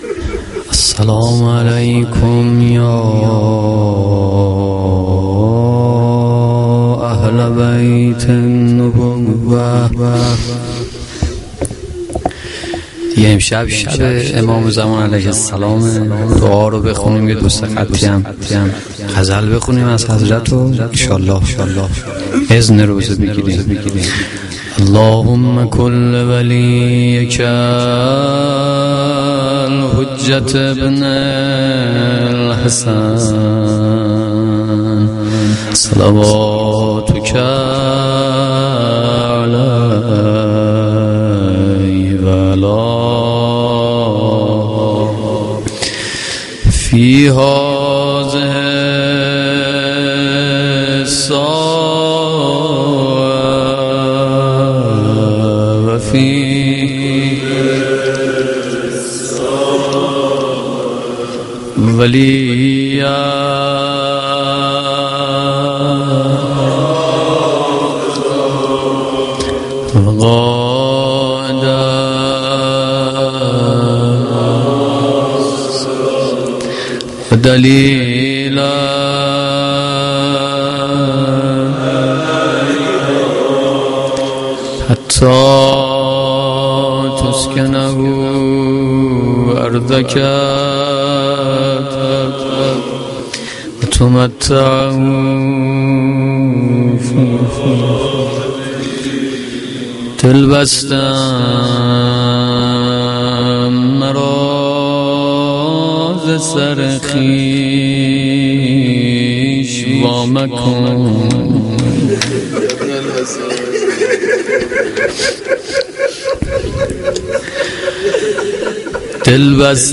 مداحی
Shab-9-Moharam-2.mp3